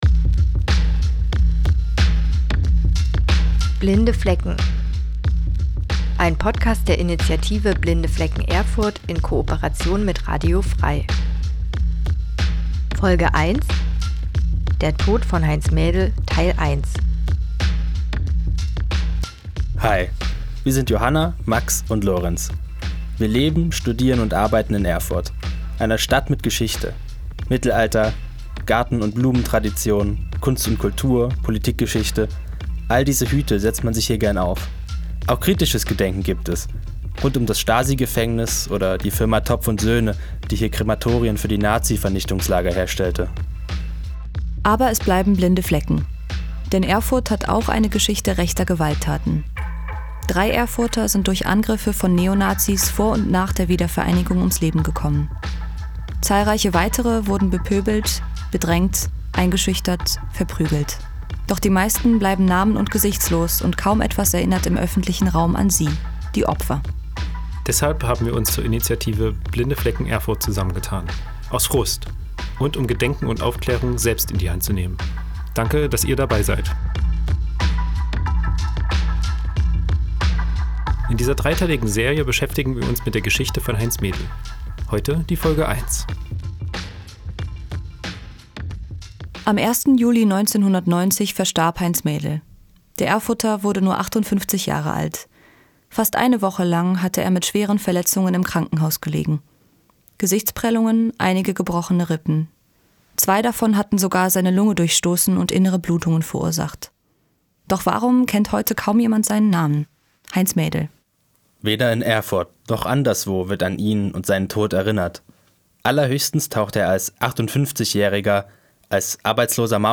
Text, Stimme, Musik: Blinde Flecken